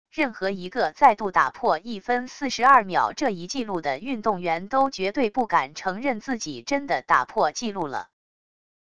任何一个再度打破1分42秒这一纪录的运动员都绝对不敢承认自己真的打破纪录了wav音频生成系统WAV Audio Player